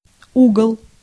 US : IPA : [ˈæŋ.gəl]